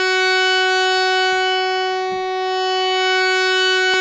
52-key15-harm-f#4.wav